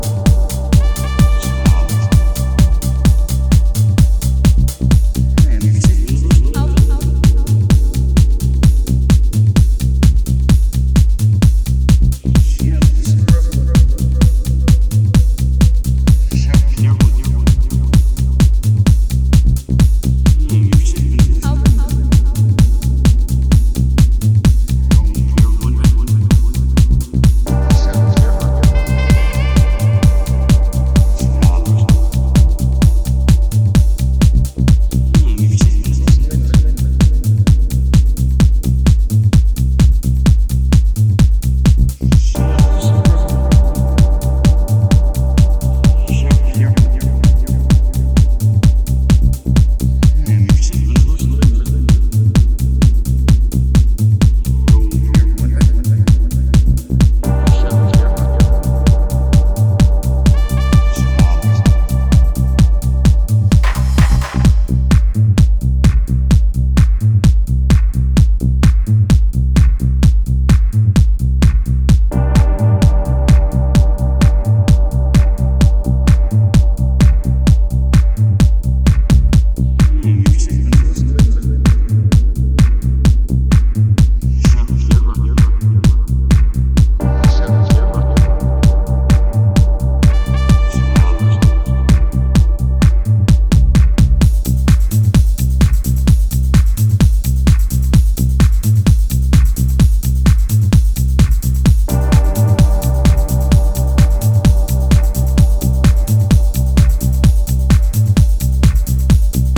a dubbed-out, sax-infused reimagining